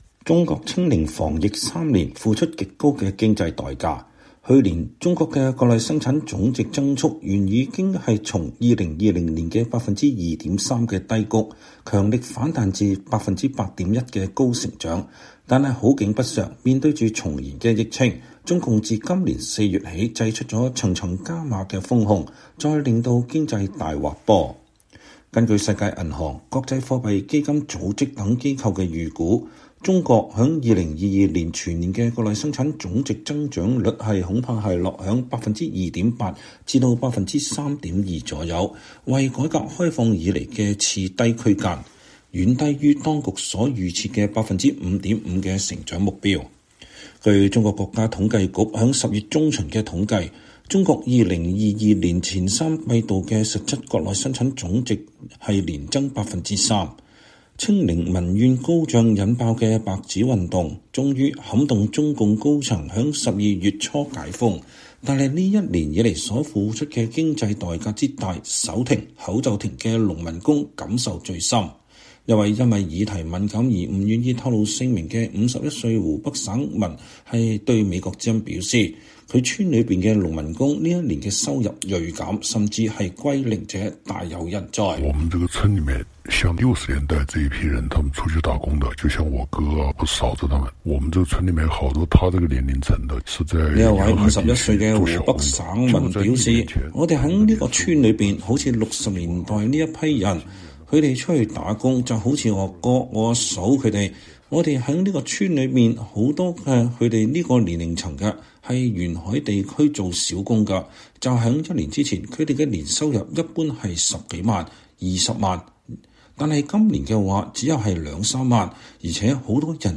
年終報導：2022年經濟再滑坡中國解封重回5%增長軌道？